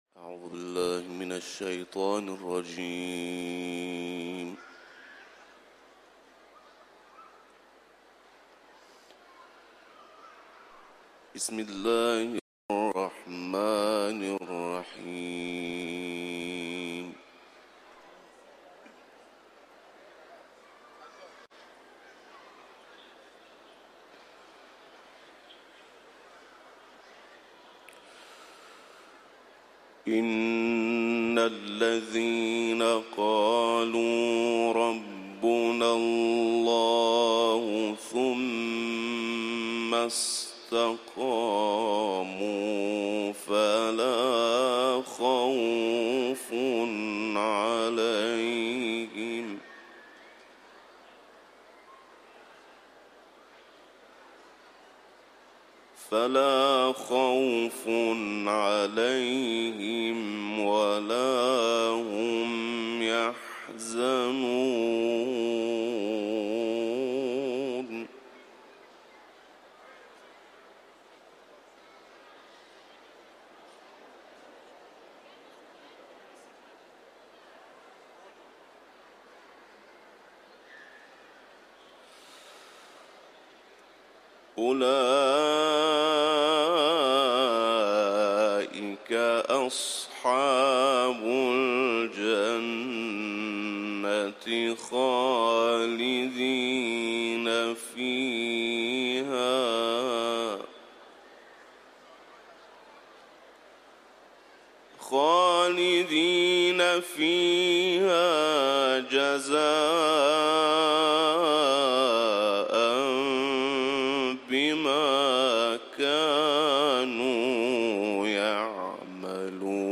Etiketler: İranlı kâri ، Kuran tilaveti ، Ahkâf suresi